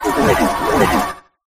cramorant-gulping.ogg